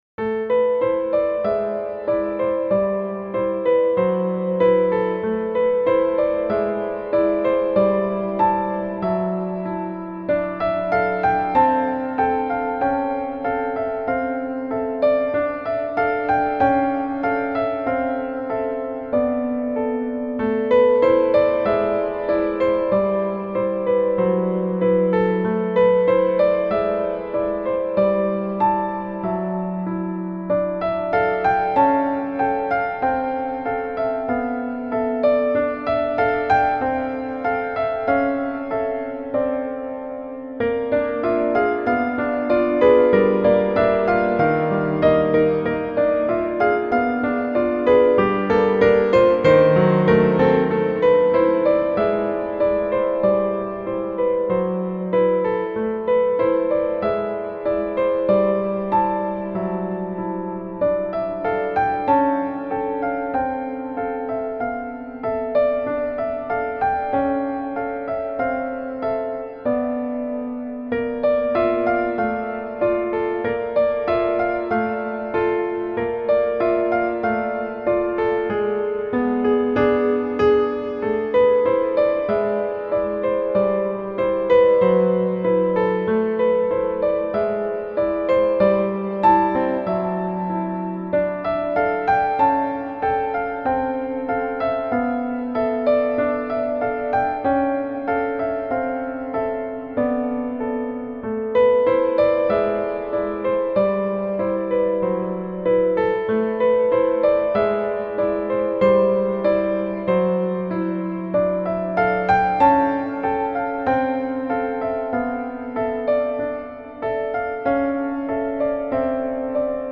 Dreamy Ambient Piano with Soft and Calming Melody
Genres: Background Music
Tempo: 95 bpm